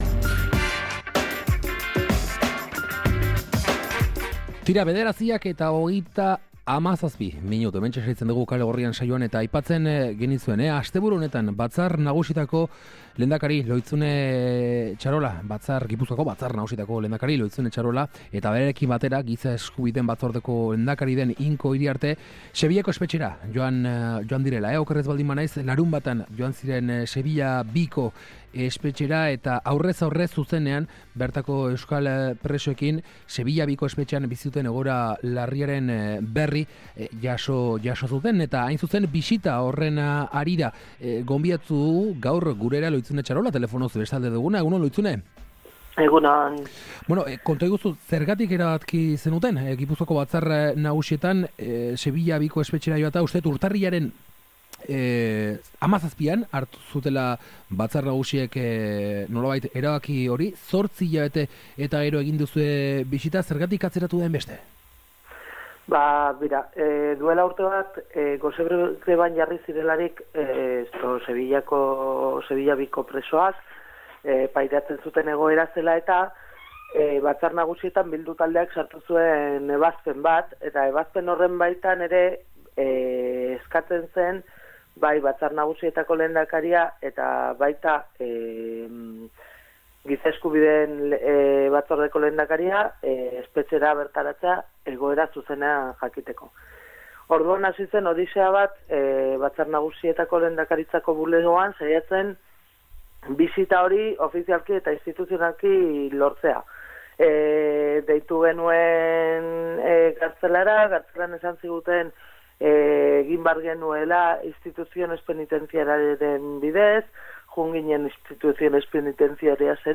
Batzar Nagusietako lehendakari Lohitzune Txarola eta Berdintasun Politiken, Giza Eskubideen eta Oroimen Historikoaren Batzordeko lehendakari Inko Iriarte Sevillako II-ko euskal presak bisitatu zituzten larunbatean. Bisita horren helburuen inguruan galdetu diogu Txarolari goizean egin diogun elkarrizketan.